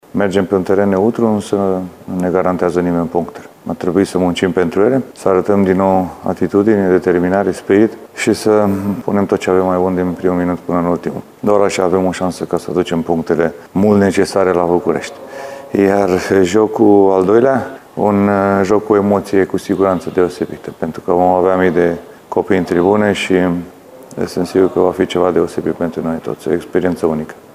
Selecționerul Edward Iordănescu a prefațat jocul cu Belarus, dar și pe cel de duminică, împotriva Andorrei (programat la București):